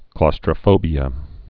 (klôstrə-fōbē-ə)